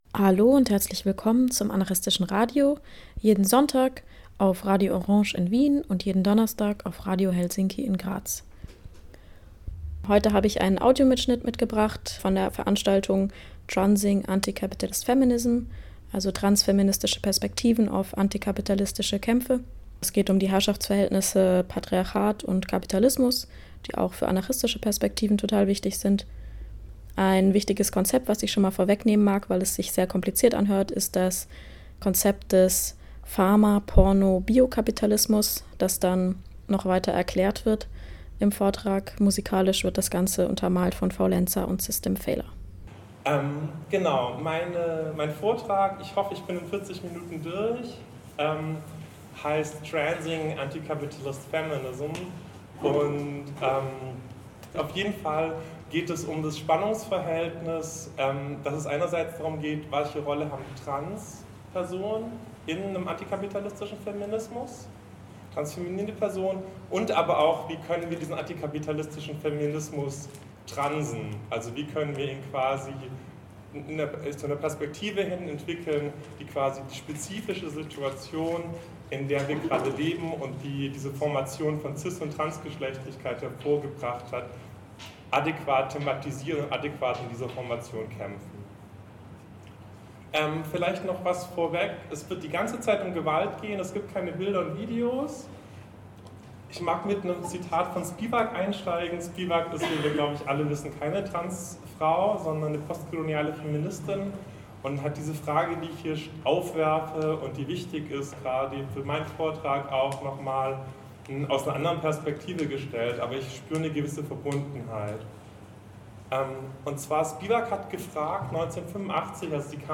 Die Sendung den anarchistischen Radios handelt von transfeministischen Kämpfen gegen Patriarchat und Kapitalismus.